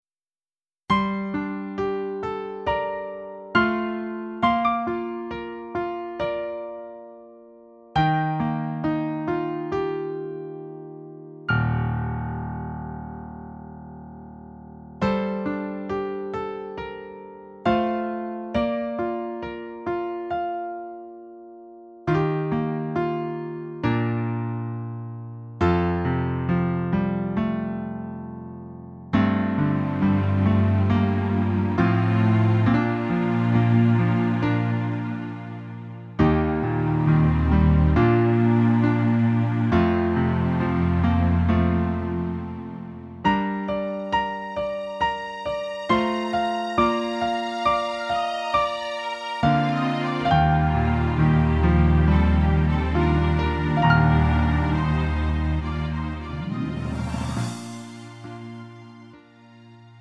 음정 -1키 3:07
장르 가요 구분 Lite MR